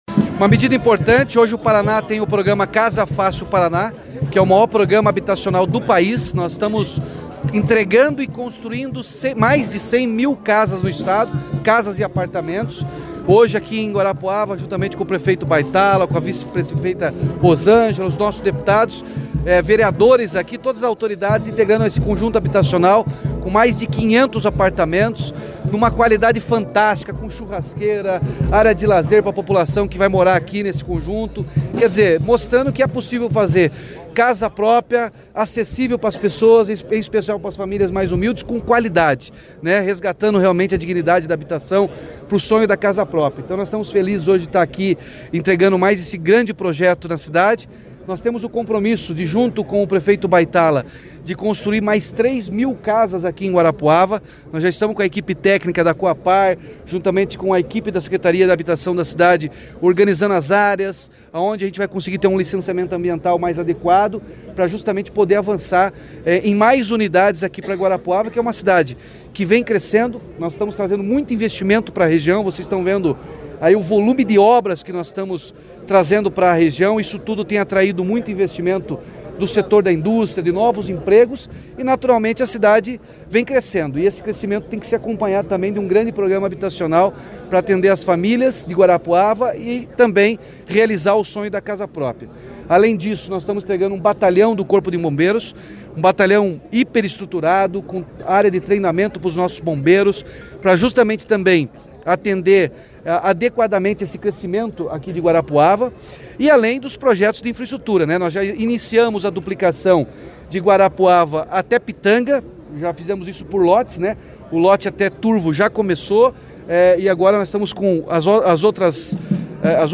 Sonora do governador Ratinho Junior sobre a entrega de residencial com 528 apartamentos em Guarapuava